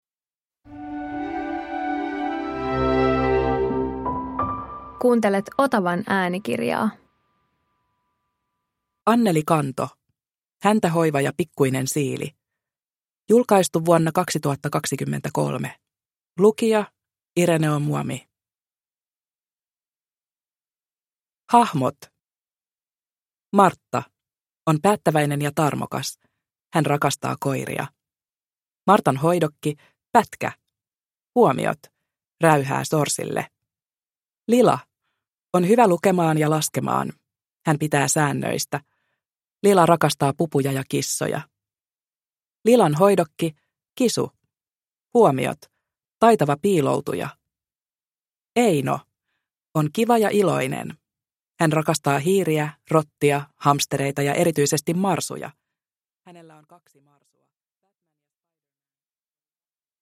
Häntähoiva ja pikkuinen siili – Ljudbok – Laddas ner